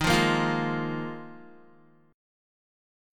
D#sus2sus4 Chord